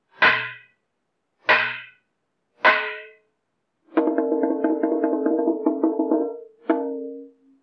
Trống Bản
rống Bản là nhạc khí màng rung gõ do người Việt Nam sáng tạo.
rống Bản là loại trống dẹt, hai mặt được bịt da, đường kính khoảng từ 30cm, tang trống bằng gỗ cao khoảng 10cm.
m thanh Trống Bản to, vang.
rống Bản được đánh bằng 2 dùi gỗ.